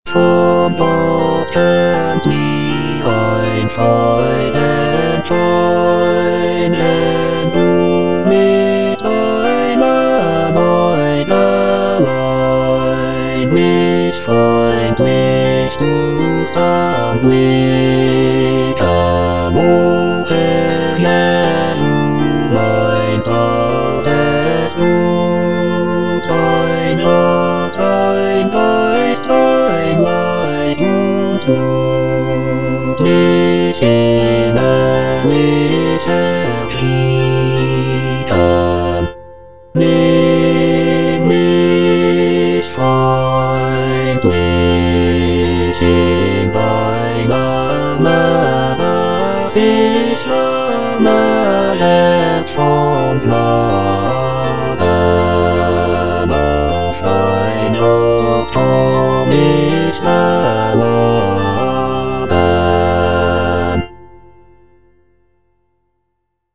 J.S. BACH - CANTATA "ERSCHALLET, IHR LIEDER" BWV172 (EDITION 2) Von Gott kömmt mir ein Freudenschein - Bass (Emphasised voice and other voices) Ads stop: auto-stop
It features a jubilant opening chorus, expressive arias, and intricate chorales. The text celebrates the coming of the Holy Spirit and the birth of the Christian Church. The music is characterized by its lively rhythms, rich harmonies, and intricate counterpoint.